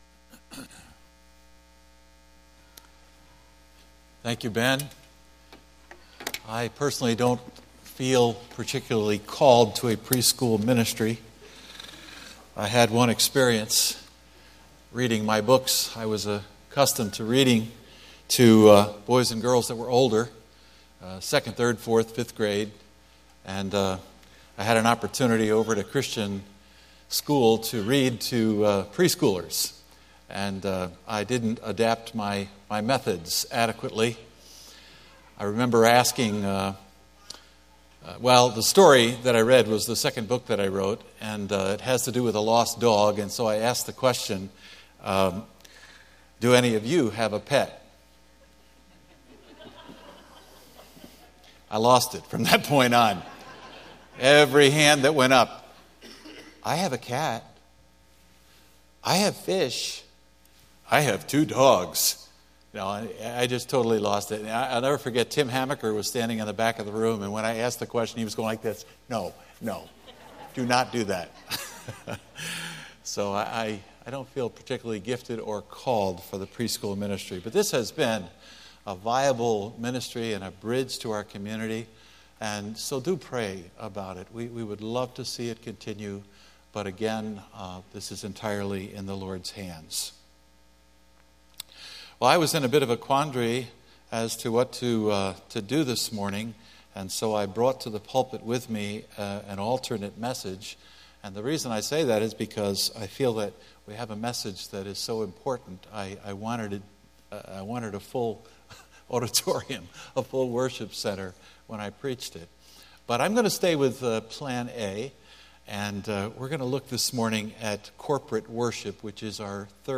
Sermons Watch the Latest Sermons on YouTube SERMONS